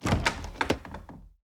Doors Gates and Chests / Chest Open 2.wav
Chest Open 2.wav